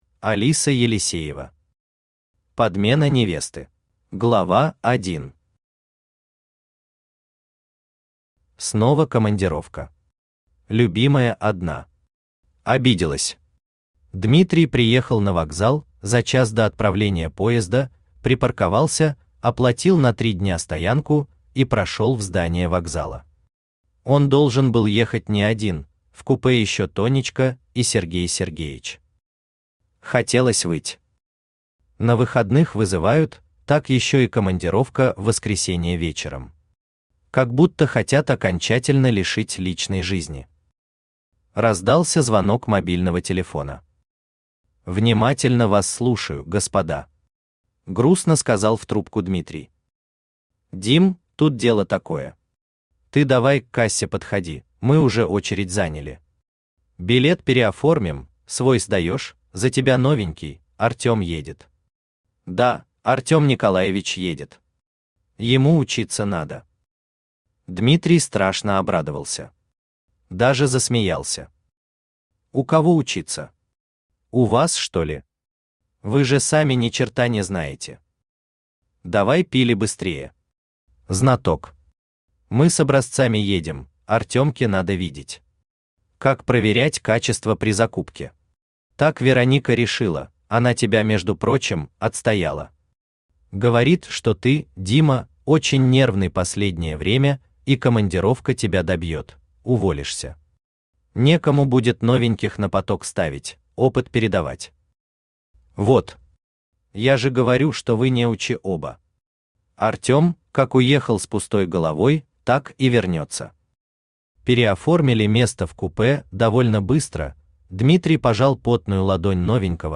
Аудиокнига Подмена невесты | Библиотека аудиокниг
Aудиокнига Подмена невесты Автор Алиса Елисеева Читает аудиокнигу Авточтец ЛитРес.